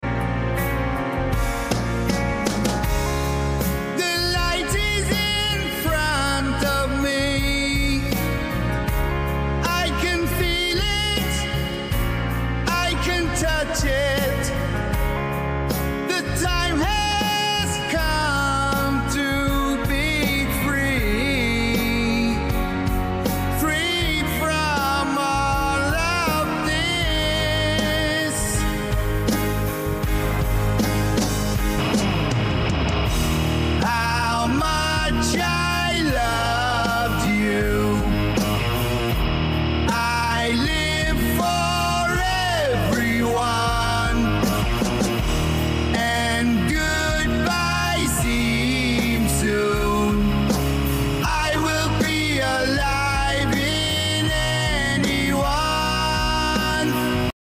AI song